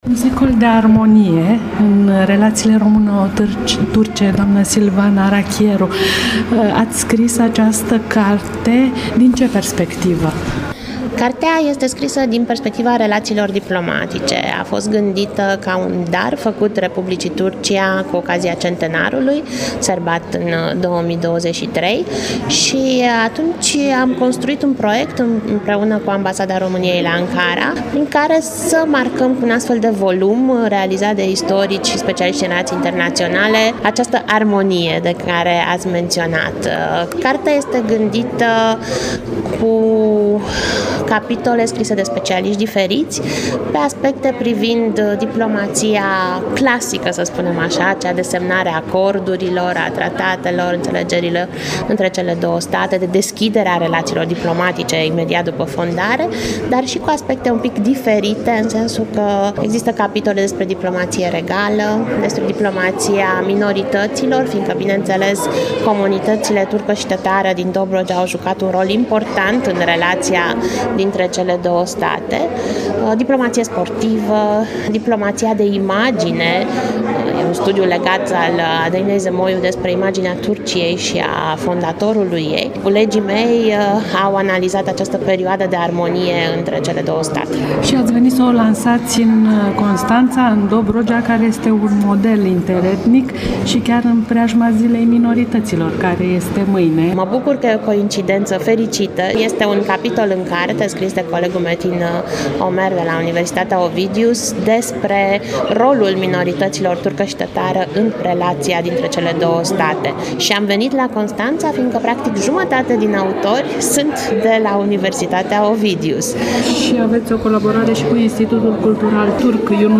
Evenimentul a fost organizat de Facultatea de Istorie și Științe Politice din cadrul UOC.
Un reportaj